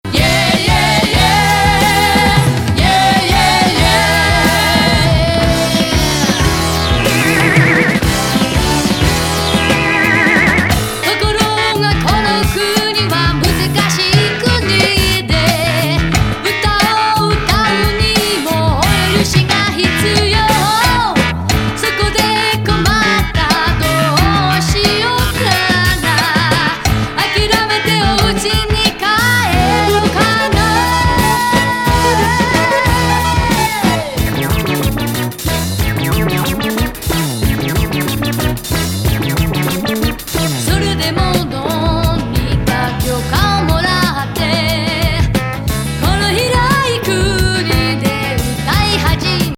ラスベガス録音作